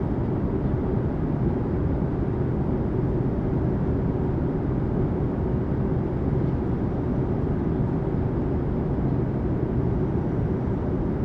background_room_tone_loop_02.wav